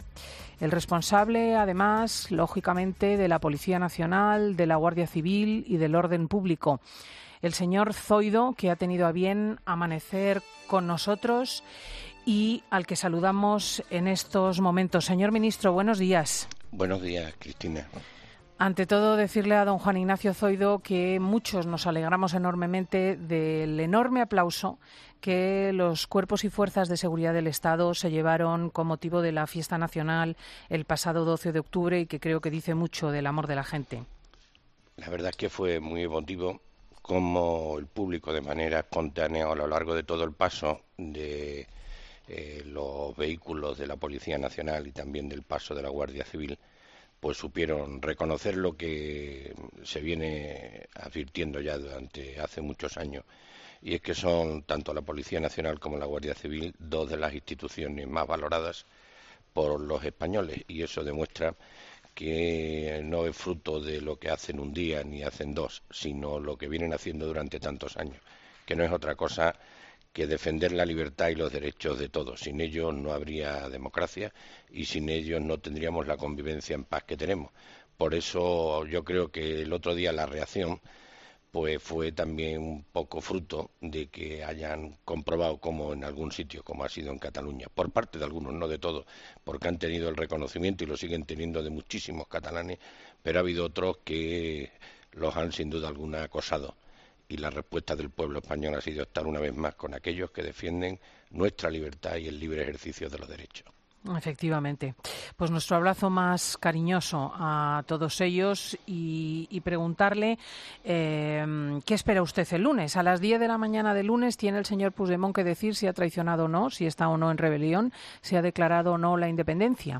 Escucha la entrevista al ministro del Interior, Juan Ignacio Zoido